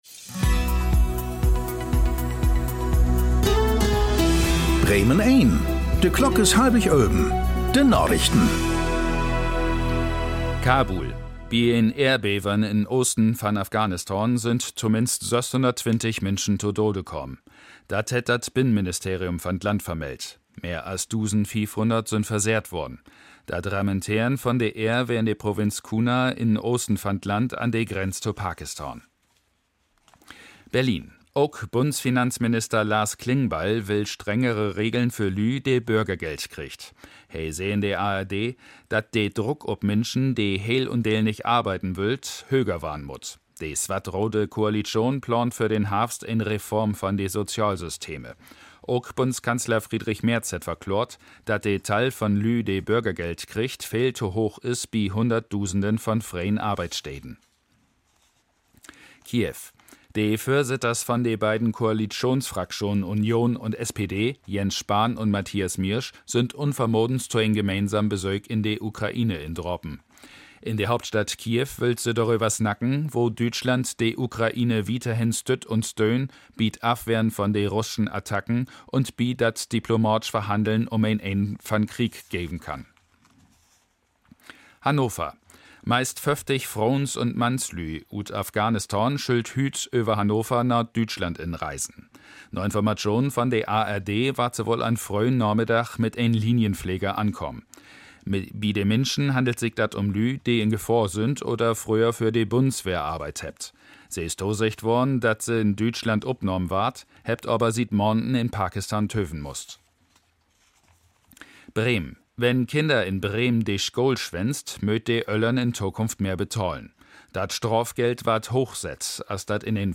Tägliche Nachrichten Nachrichten Thu Apr 01 11:24:10 CEST 2021 Radio Bremen Radio Bremen
Aktuelle plattdeutsche Nachrichten werktags auf Bremen Eins und hier für Sie zum Nachhören.